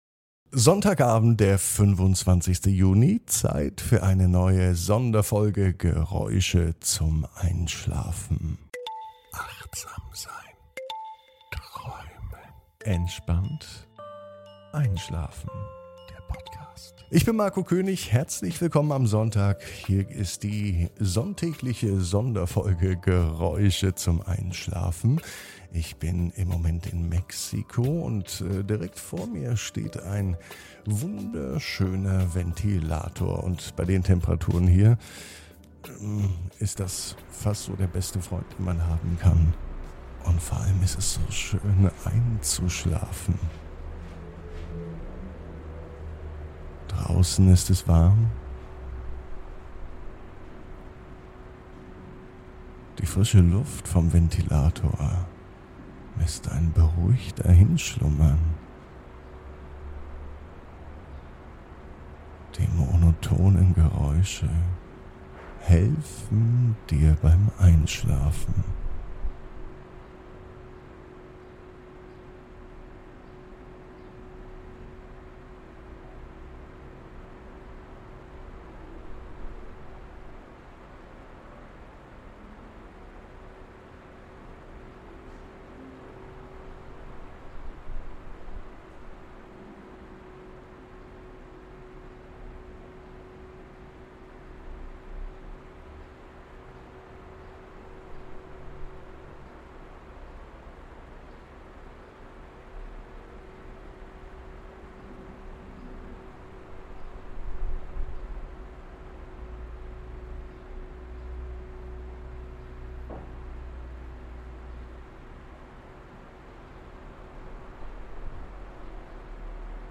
Geräusche zum Einschlafen: Hotelzimmer-Idylle: Entspannung mit dem sanften Summen eines Ventilators ~ Entspannt einschlafen - Meditation & Achtsamkeit für die Nacht Podcast
In dieser Episode des Einschlafpodcasts entführen wir dich in die gemütliche Atmosphäre eines Hotelzimmers, begleitet vom beruhigenden Geräusch eines Ventilators.
Tauche ein in die entspannende Kulisse und lasse das sanfte Summen des Ventilators deine Gedanken beruhigen.
Das monotone Rauschen schafft eine wohltuende Hintergrundkulisse, die dir hilft, zur Ruhe zu kommen und einzuschlafen.